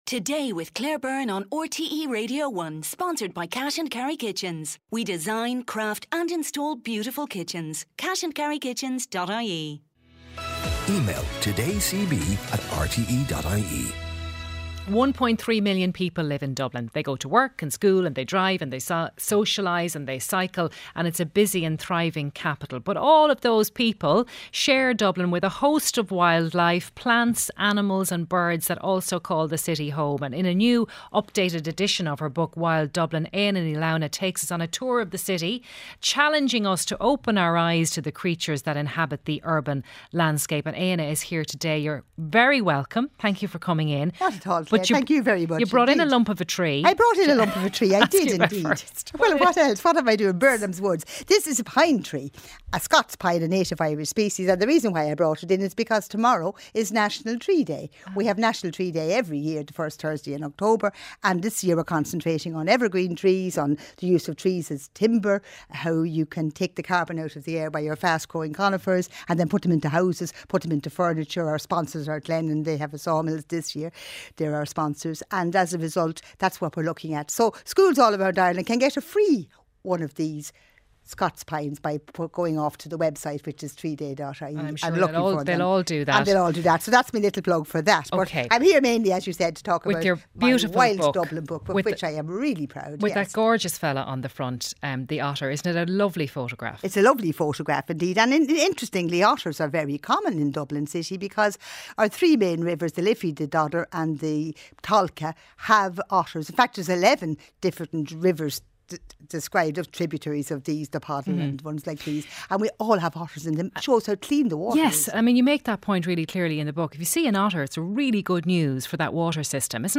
Highlights from the mid-morning show with Claire Byrne, featuring stories of the day, sharp analysis, features, sports and consumer interest items.